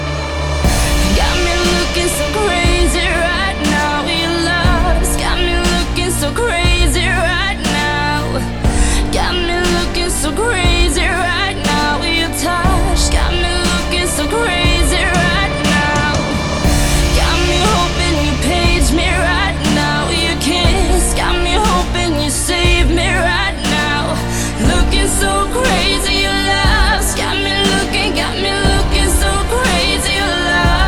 • Качество: 320, Stereo
лирика
чувственные
красивый женский вокал